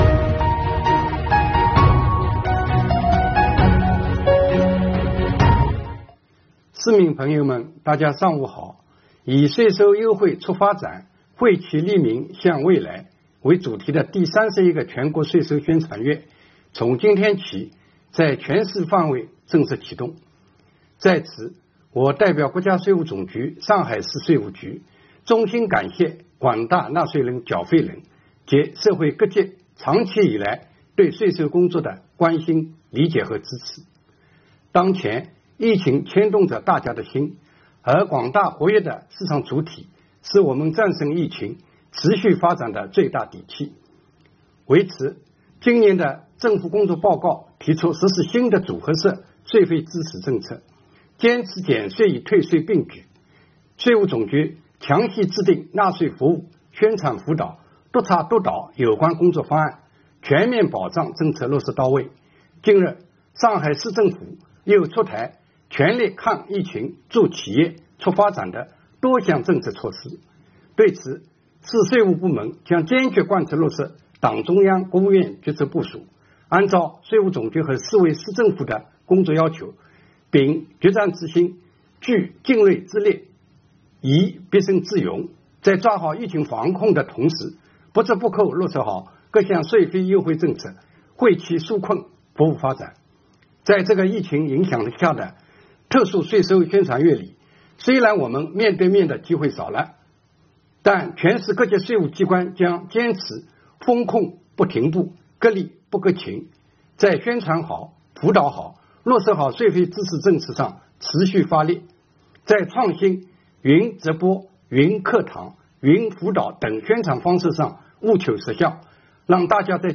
今天上午，国家税务总局上海市税务局党委书记、局长马正文通过视频的方式发表致辞，正式启动本市第31 个税收宣传月。